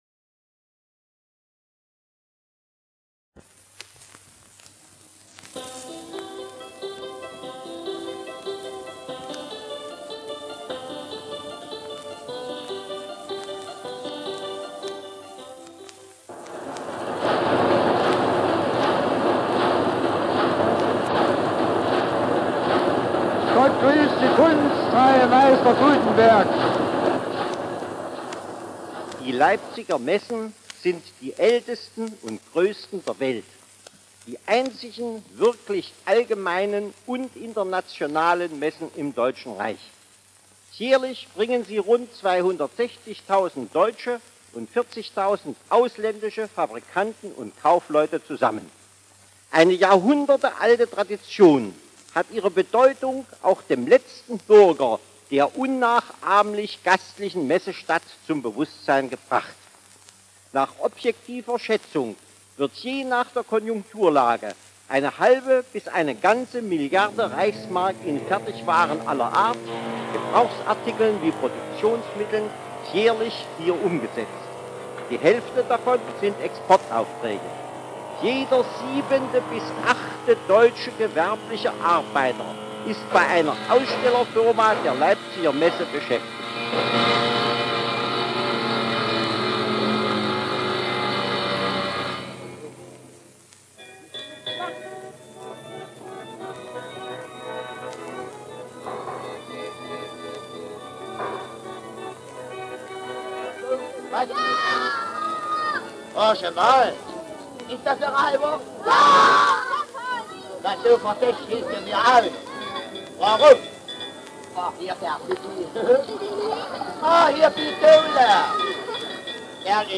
Original Format: Shellac Record 78rpm
Channels: MONO